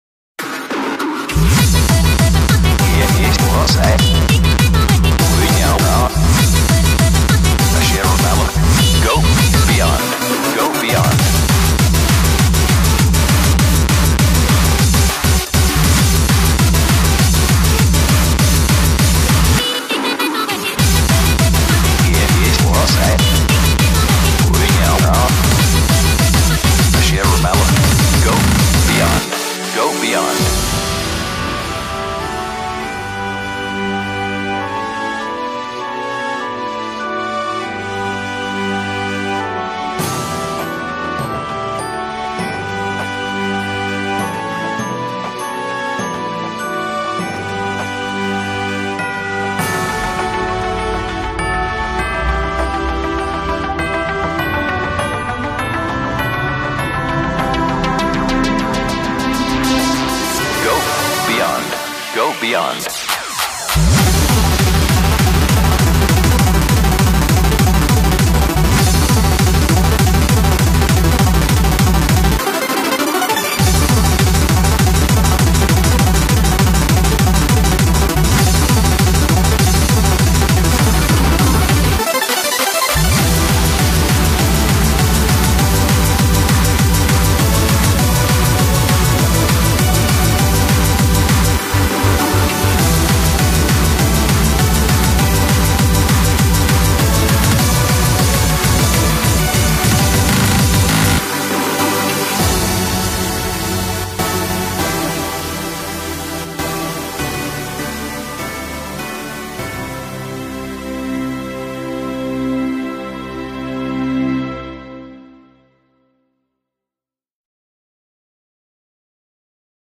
BPM50-200